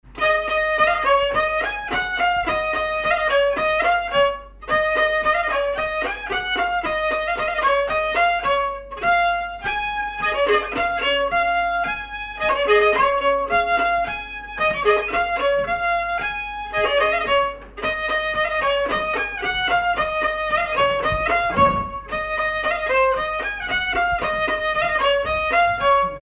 danse : polka piquée
circonstance : bal, dancerie
Pièce musicale inédite